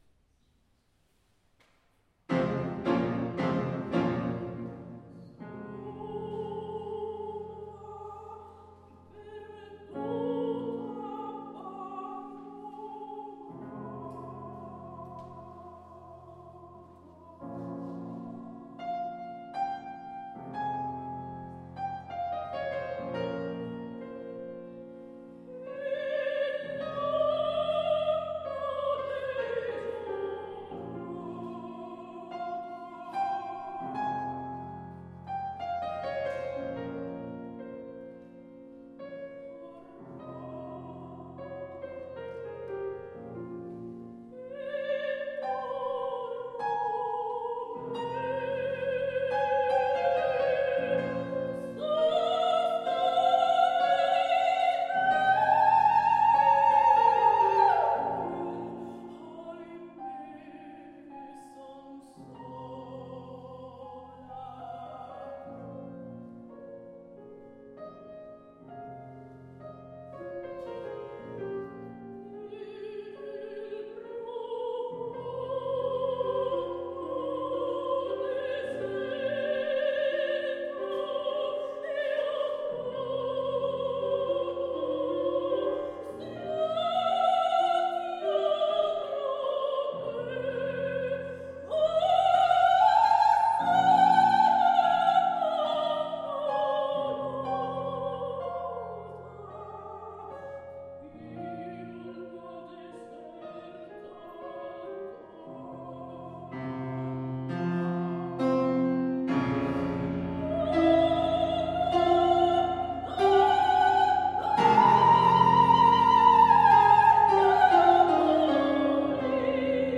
Soprano
Pianoforte